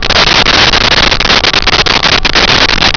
Sfx Parts Rack Hiss Loop
sfx_parts_rack_hiss_loop.wav